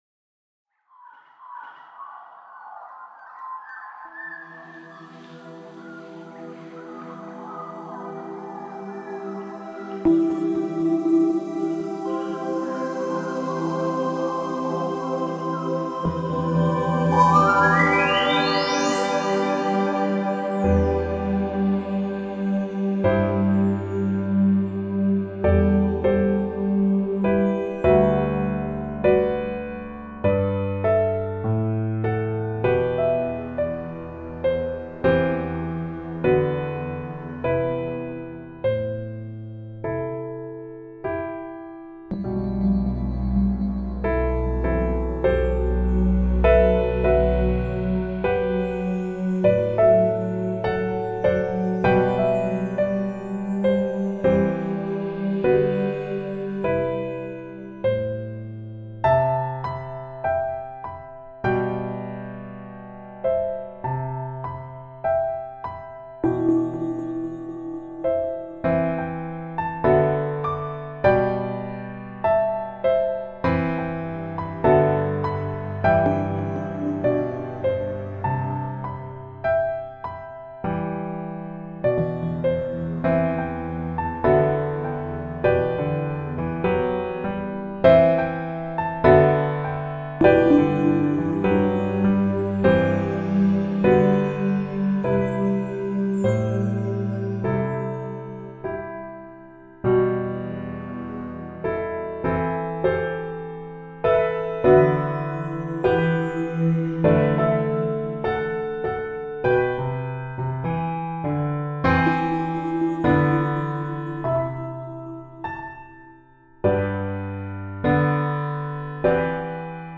Singing Australian Magpies
I couldn’t resist sampling their song to introduce a little piano piece I wrote last year.
pianothemeinemfinal-high-quality.mp3